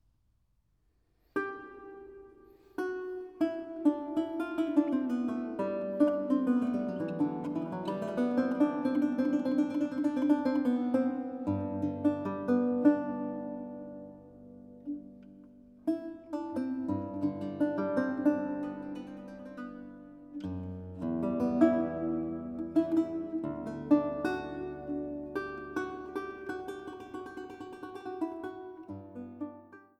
Sopran
Cembalo
Violoncello
Théorbe